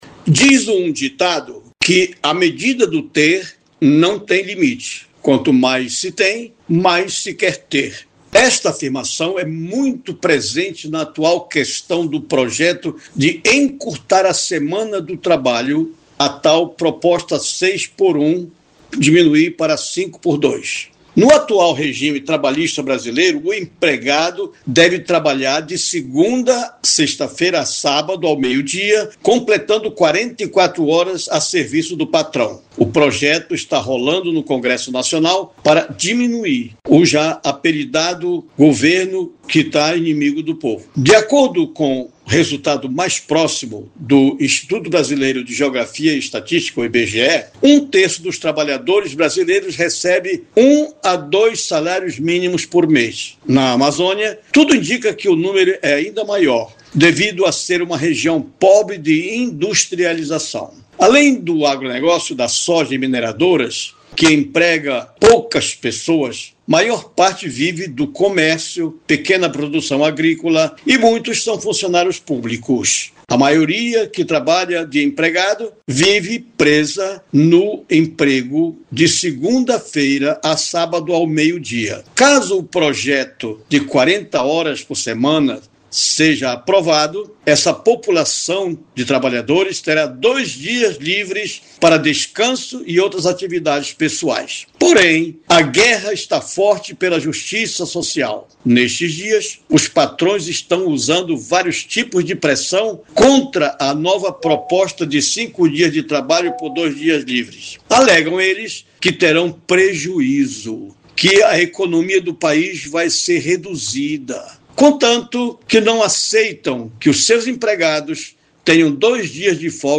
Acompanhe o editorial